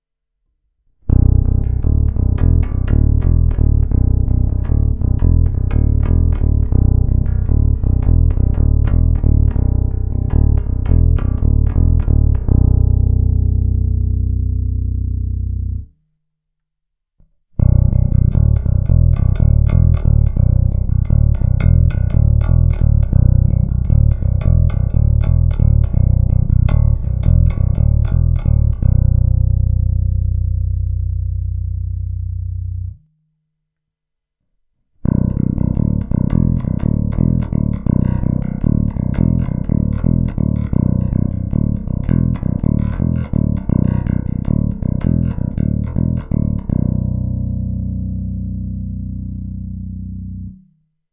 Zvuk je naprosto typický, a hlavně naprosto prvotřídní Jazz Bass, a to včetně skvěle artikulující struny H, na čemž se významnou měrou jistě podílí i prodloužená menzura.
Není-li uvedeno jinak, následující nahrávky jsou provedeny rovnou do zvukové karty a s plně otevřenou tónovou clonou.
Ukázka struny H